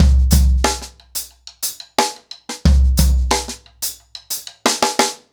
InDaHouse-90BPM.33.wav